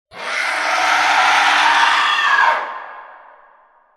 Download Demonic sound effect for free.
Demonic